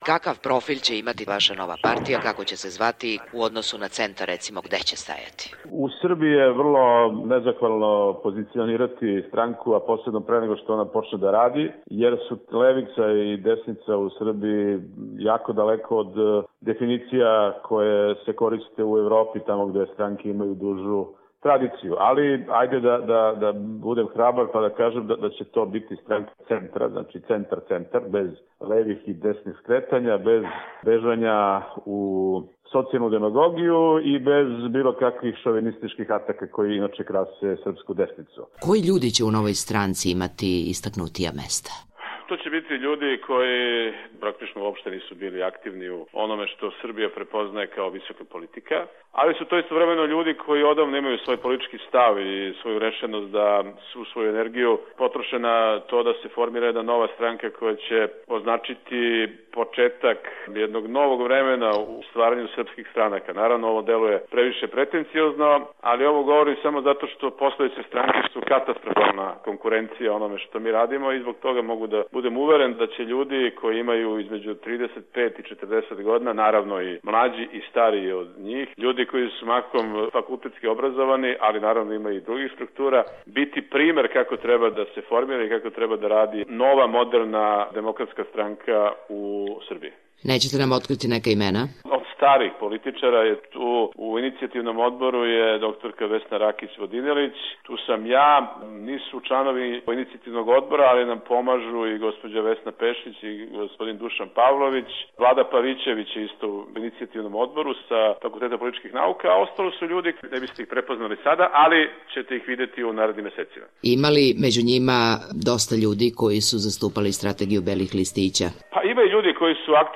Intervju nedelje: Zoran Živković